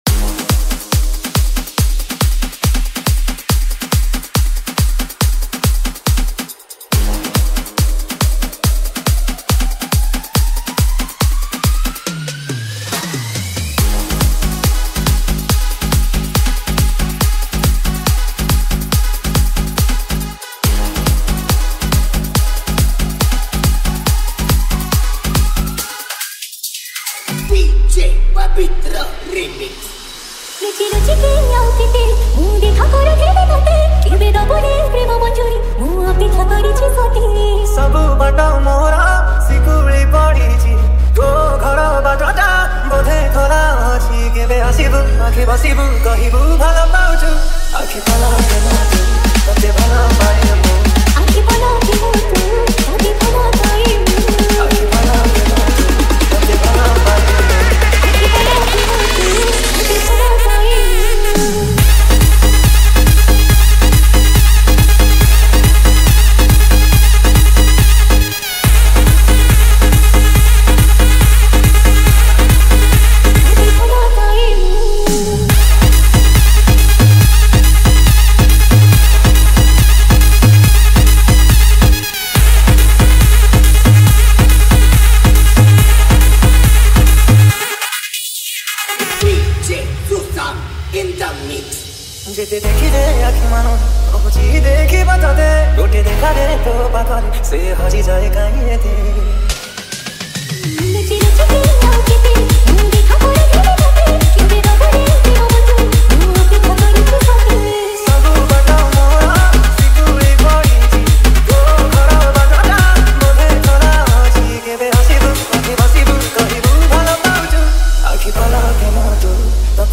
Category:  New Odia Dj Song 2025